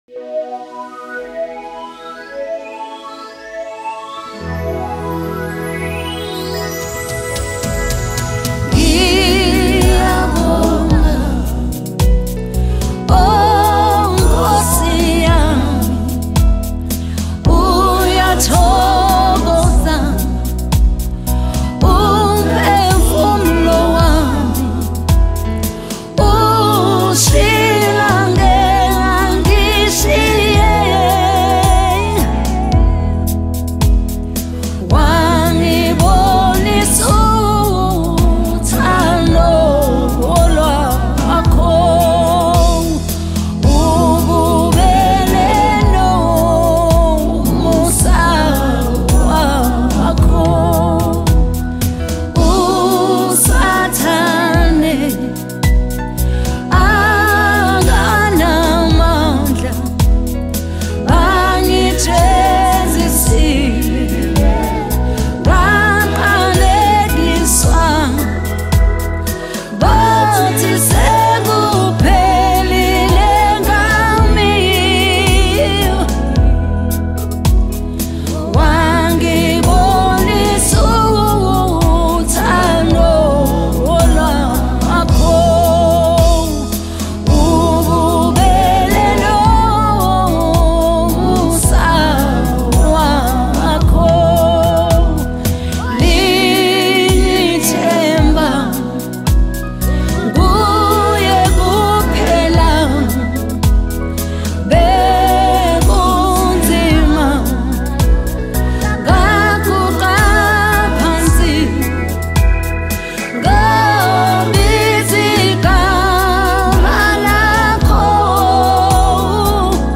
February 4, 2025 Publisher 01 Gospel 0